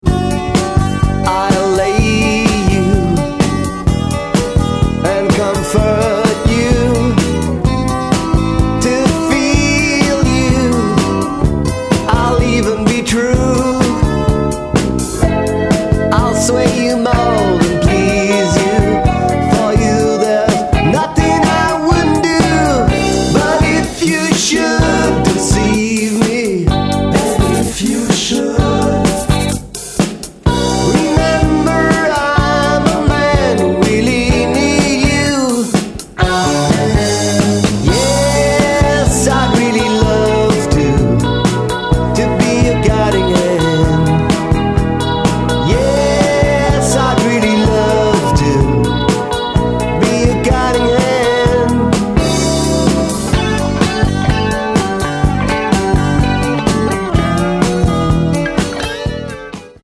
Four brothers and a cousin who play music.